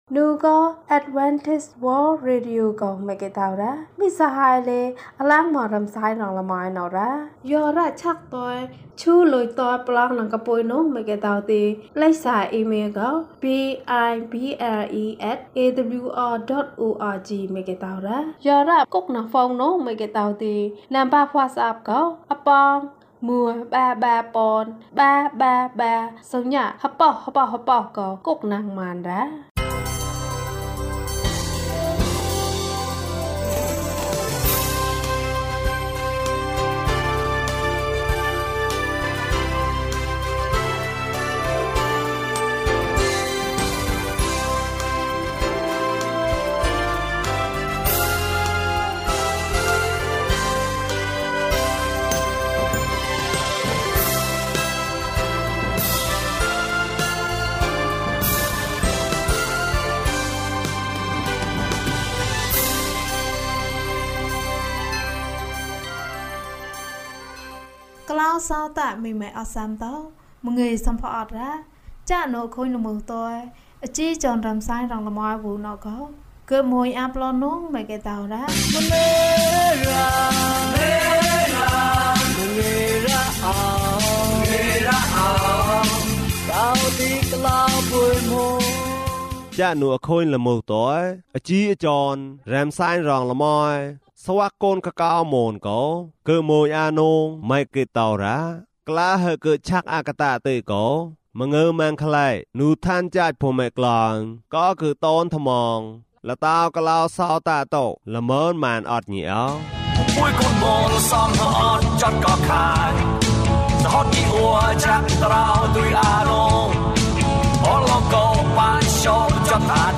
နည်းလမ်းနှစ်ခု။ ကျန်းမာခြင်းအကြောင်းအရာ။ ဓမ္မသီချင်း။ တရားဒေသနာ။